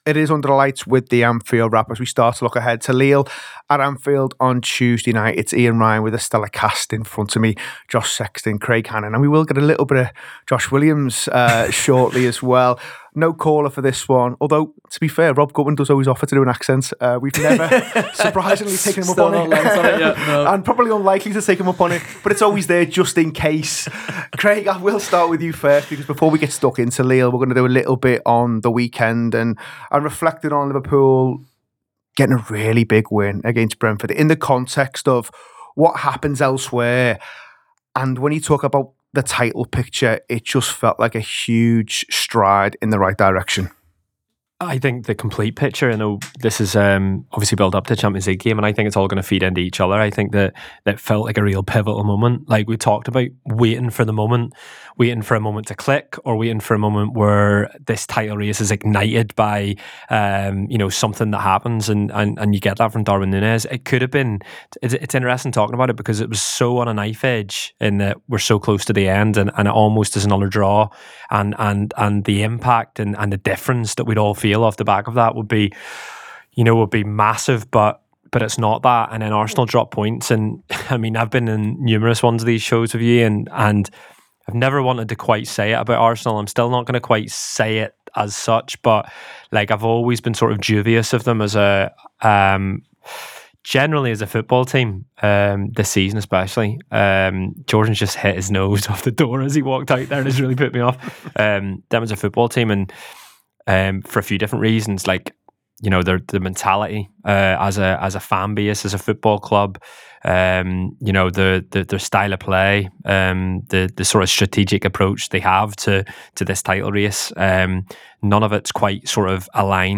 Below is a clip from the show – subscribe for more on Liverpool v Lille in the Champions League…